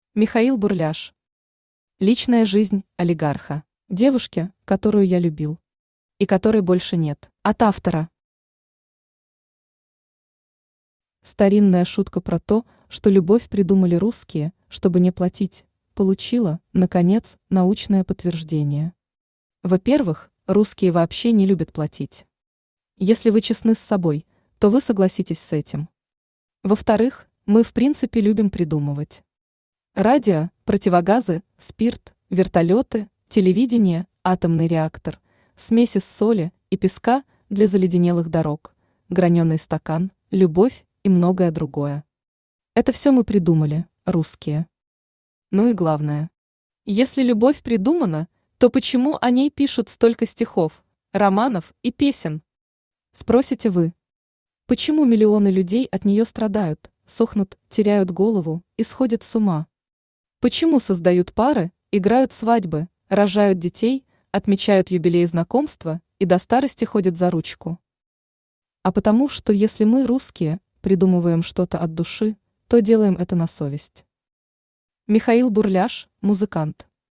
Аудиокнига Личная жизнь олигарха | Библиотека аудиокниг
Aудиокнига Личная жизнь олигарха Автор Михаил Бурляш Читает аудиокнигу Авточтец ЛитРес.